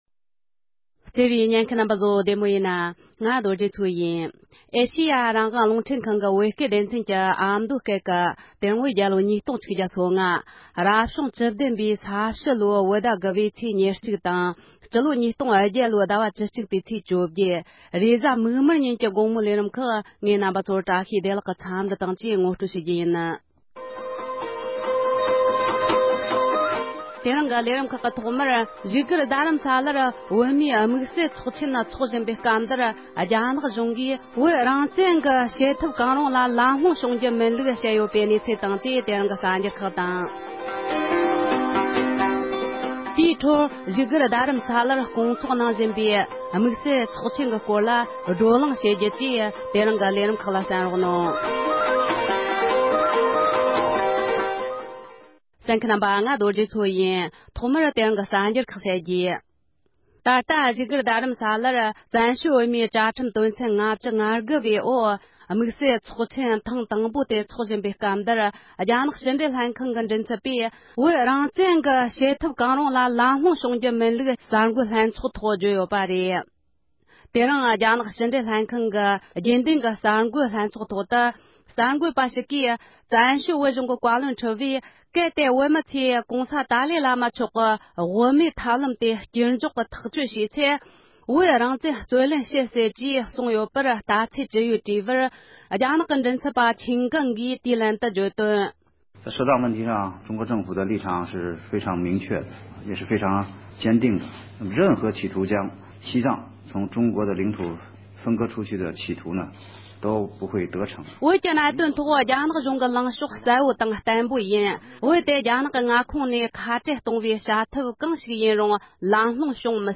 བཙན་བྱོལ་བོད་གཞུང་བཀའ་ཤག་གི་གསུང་བཤད།
བཞུགས་སྒར་རྡ་རམ་ས་ལར་དམིགས་བསལ་ཚོགས་ཆེན་དབུ་འབྱེད་གནང་སྐབས་བཙན་བྱོལ་བོད་གཞུང་བཀའ་ཤག་ནས་གསུང་བཤད།